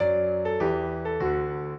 piano
minuet11-11.wav